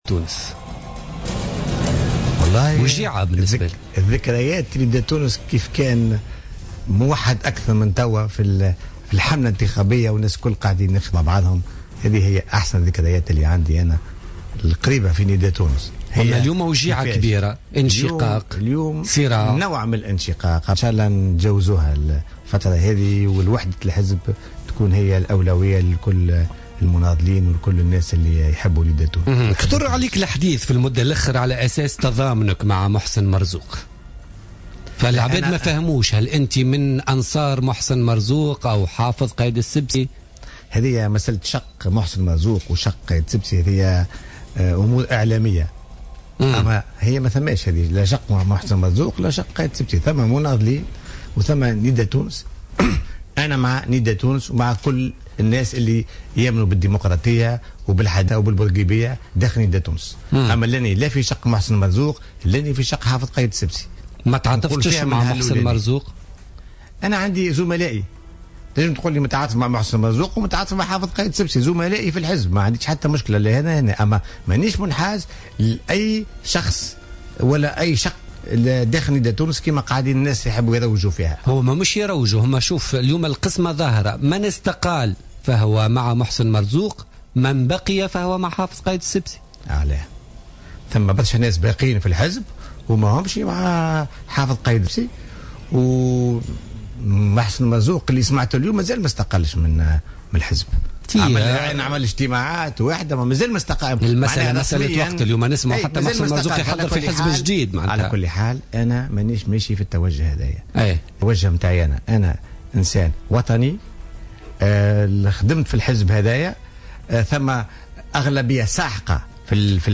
أعلن نائب رئيس حركة نداء تونس فوزي اللومي وضيف برنامج بوليتكا لليوم الأربعاء 30 ديسمبر 2015 أنه سينشأ على غرار شق محسن مرزوق وشق حافظ السبسي شقا جديدا ثالثا سيتزعمه.